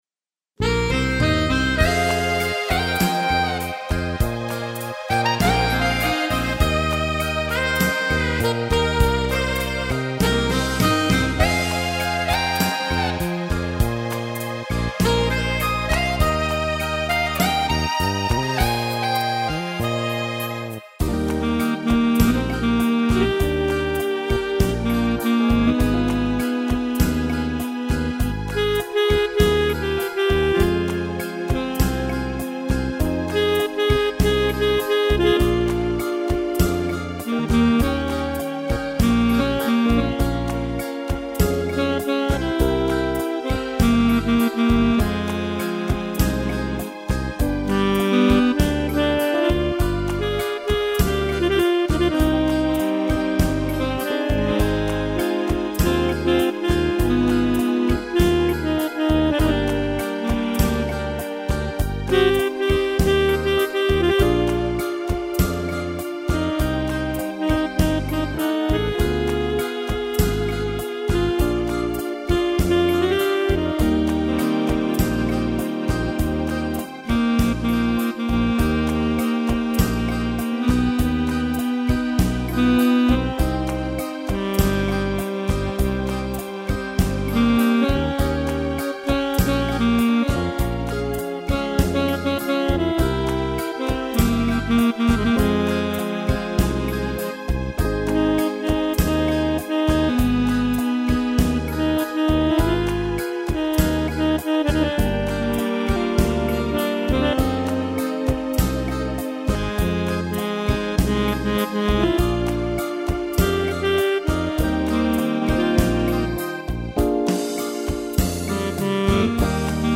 violão
piano e flauta